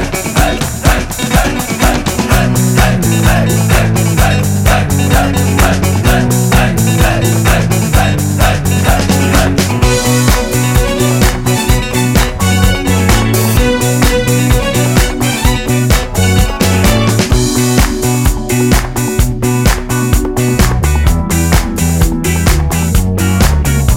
No Male Vocal Disco 4:41 Buy £1.50